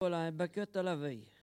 Enquête Arexcpo en Vendée-Lucus
Catégorie Locution